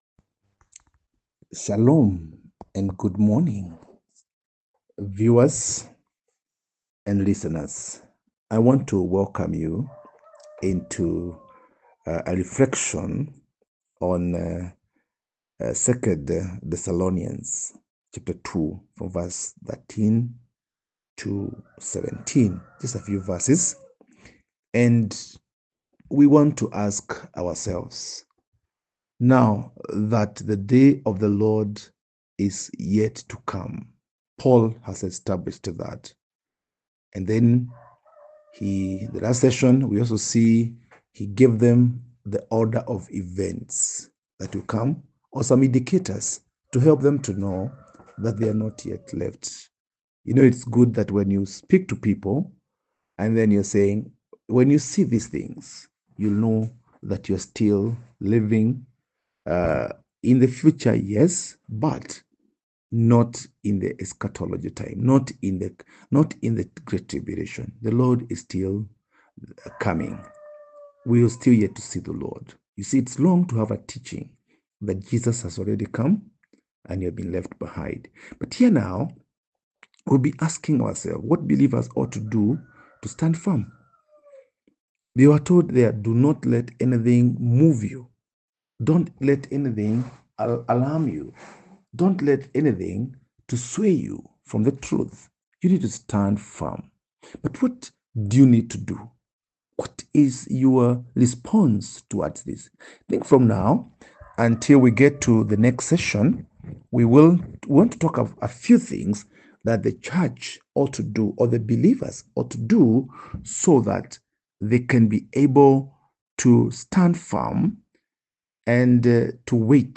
Post Lesson Teaching Summary